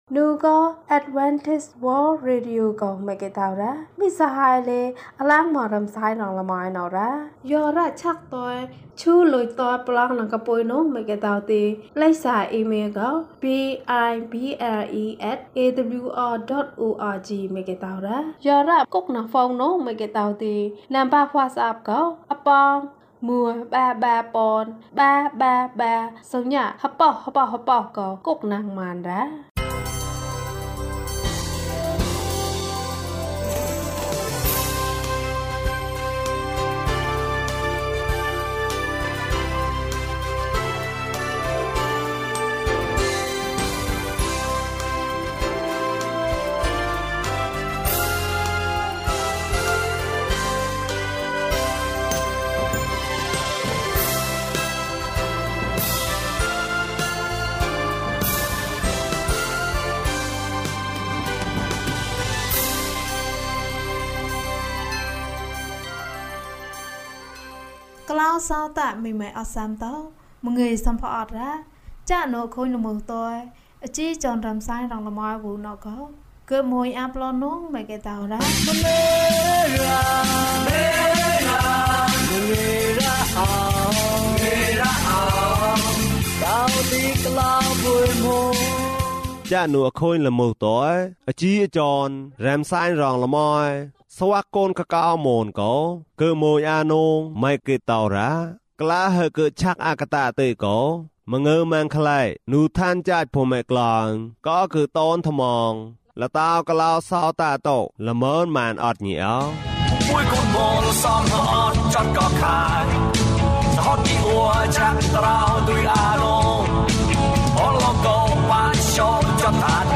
မေတ္တာတရား ကြီးမြတ်သည်။ ကျန်းမာခြင်းအကြောင်းအရာ။ ဓမ္မသီချင်း။ တရားဒေသနာ။